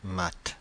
Ääntäminen
Synonyymit mathématiques Ääntäminen France (Paris): IPA: /mat/ Paris: IPA: [mat] Haettu sana löytyi näillä lähdekielillä: ranska Käännöksiä ei löytynyt valitulle kohdekielelle.